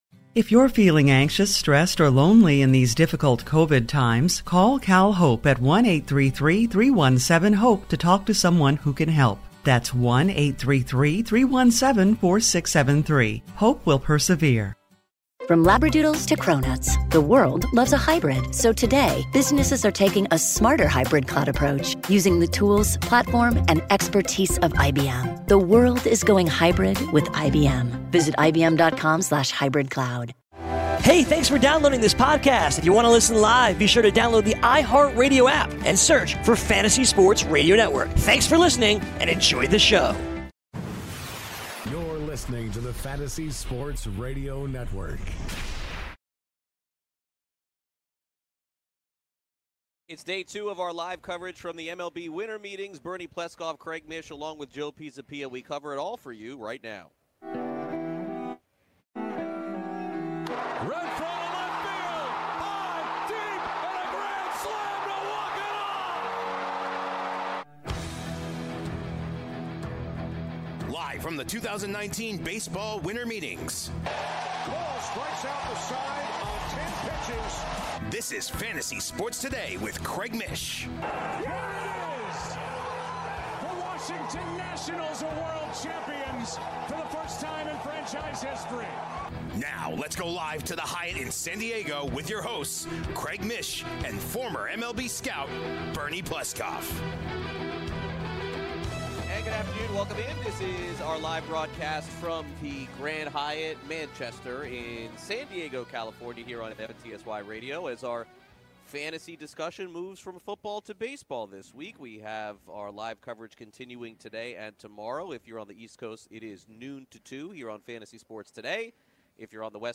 LIVE FROM THE MLB WINTER MEETINGS Day 2